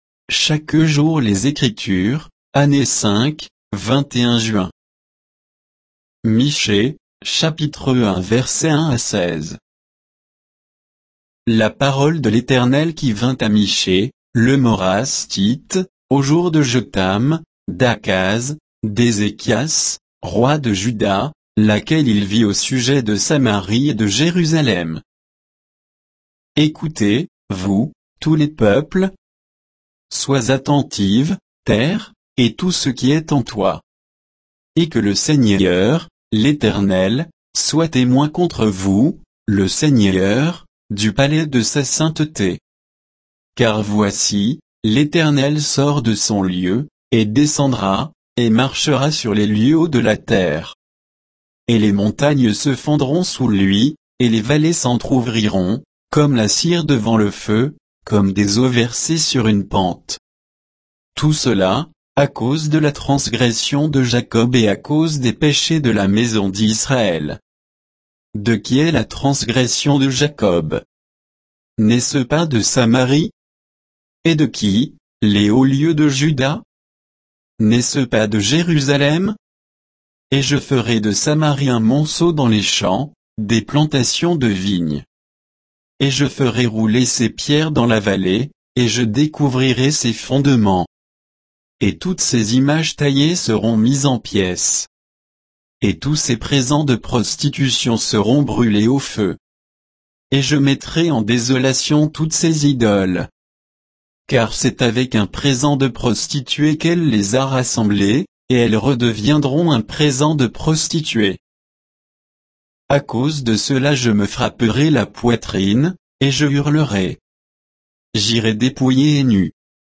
Méditation quoditienne de Chaque jour les Écritures sur Michée 1, 1 à 16